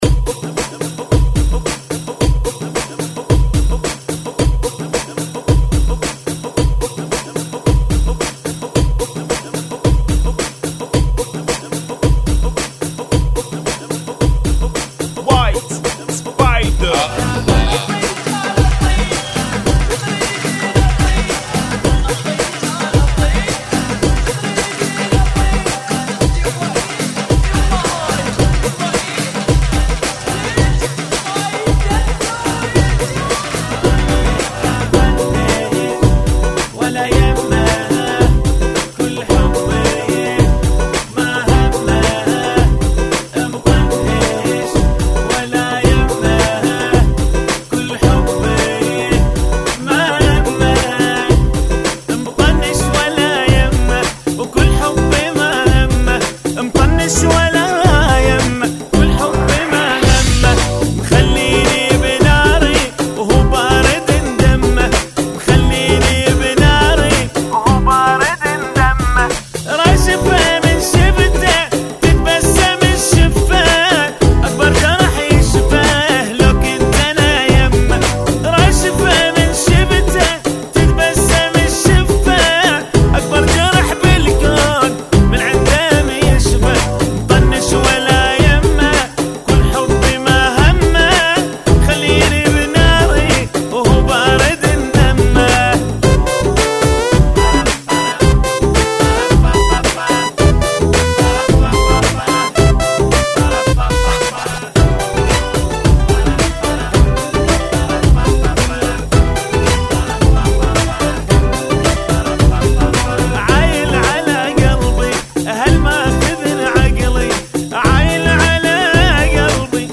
Funky [ 110 Bpm ]